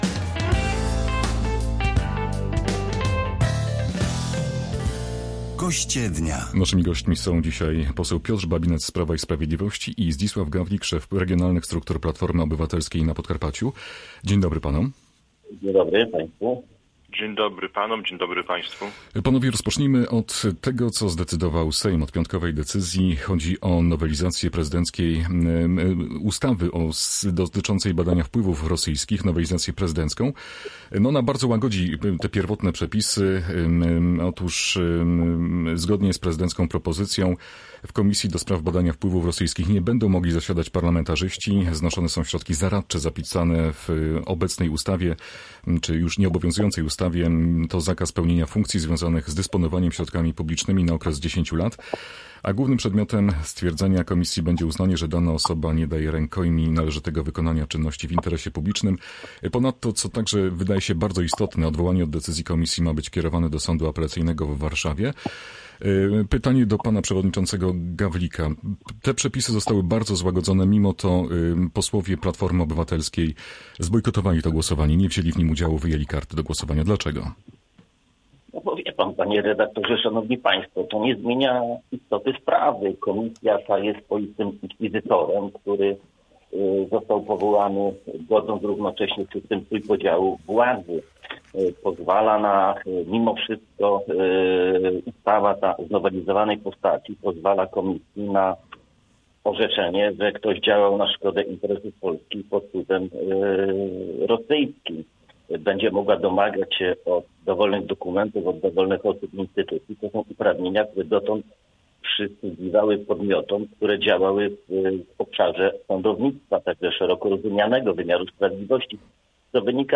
Goście naszej porannej audycji Kalejdoskop są podzieleni w ocenie przyjętej przez Sejm nowelizacji ustawy o komisji do spraw badania wpływów rosyjskich w Polsce. Zdaniem Zdzisława Gawlika z Platformy Obywatelskiej nowe rozwiązania nadal godzą w system trójpodziału władzy.